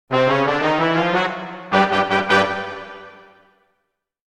Funny Sounds / Sound Effects 13 Jan, 2026 Next Level Victory Sound Effect Read more & Download...
Next-level-victory-sound-effect.mp3